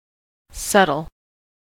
subtle: Wikimedia Commons US English Pronunciations
En-us-subtle.WAV